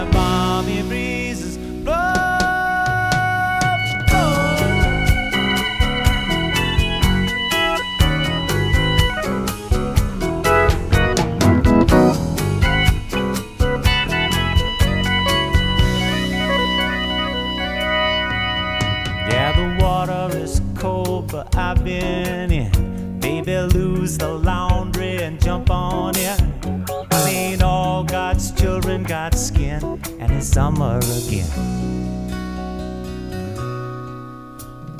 Soft Rock
Жанр: Поп музыка / Рок